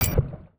UIClick_Menu Water Splash Metal Hit 03.wav